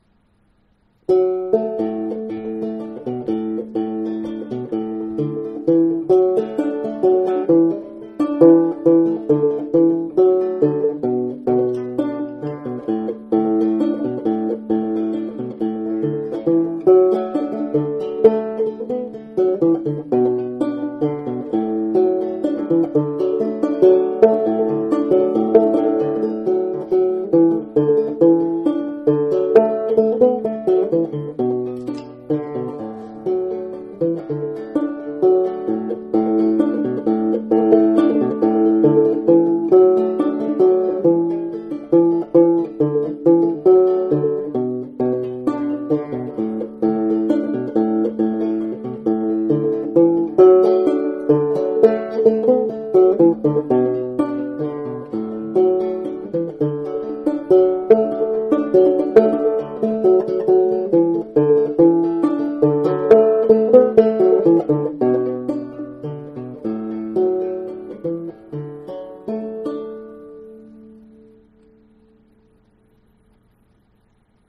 I have made some recordings of my banjos and put them out here for you to listen to.
Minstrel Banjo built from scratch